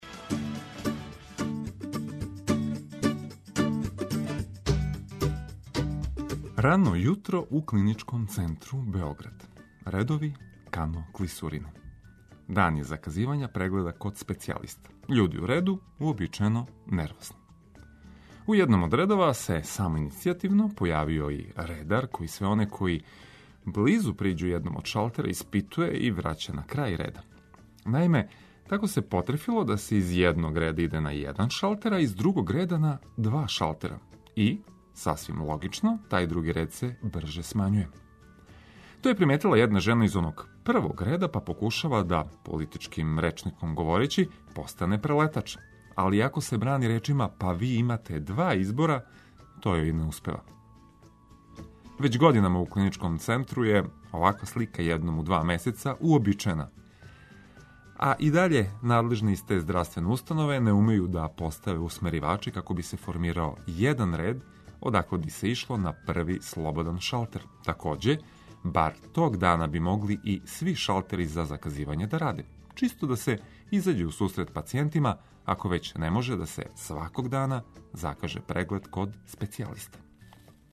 Буђење уз важне вести и велике хитове.